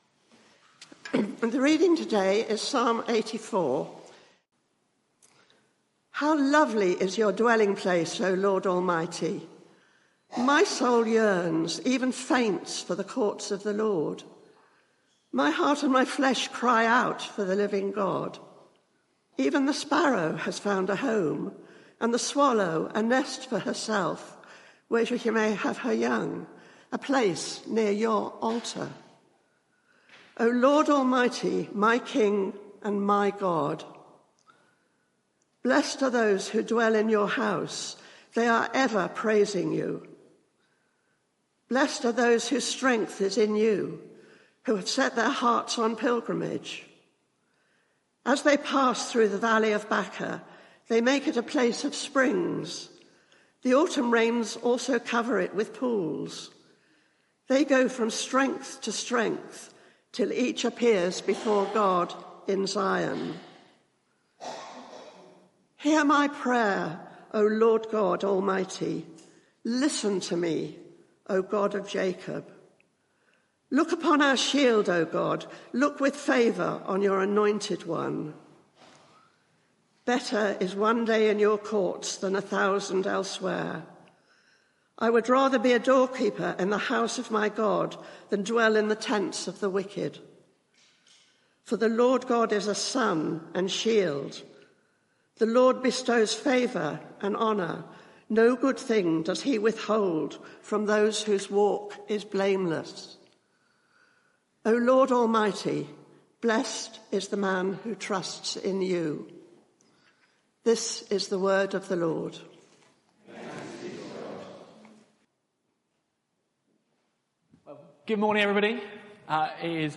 Media for 9:15am Service on Sun 20th Aug 2023 09:15 Speaker
Sermon (audio)